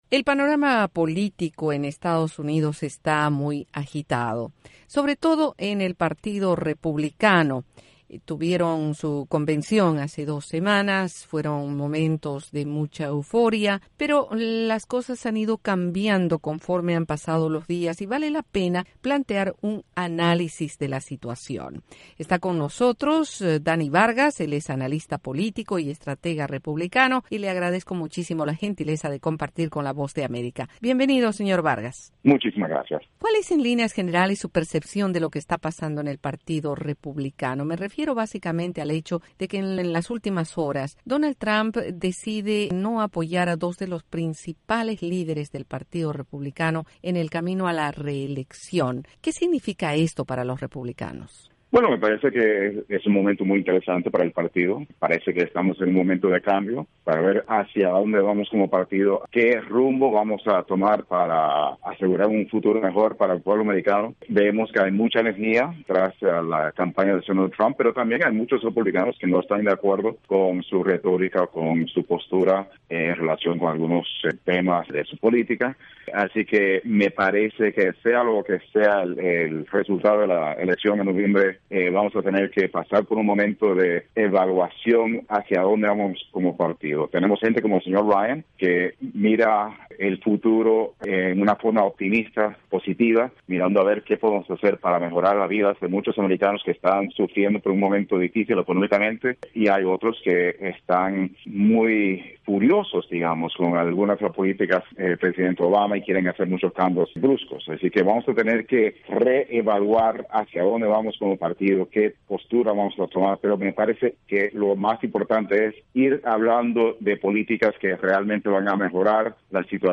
Colaboración con la entrevista de esta crónica